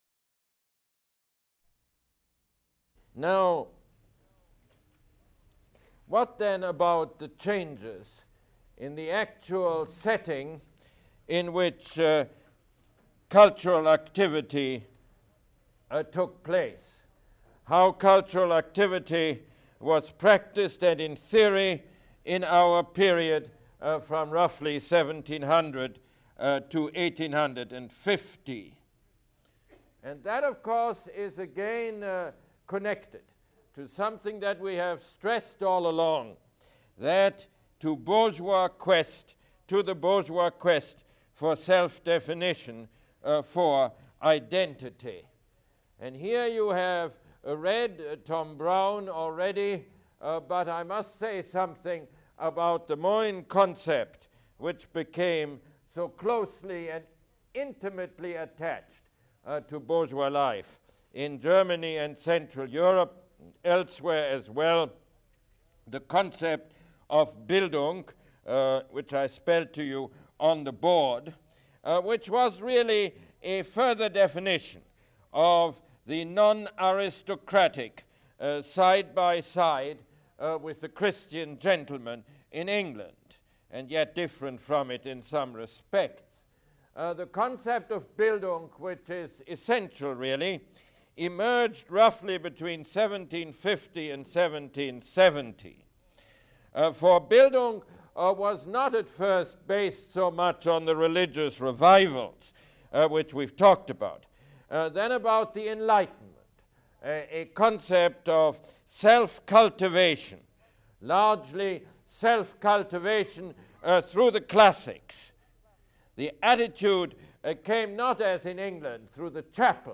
Mosse Lecture #37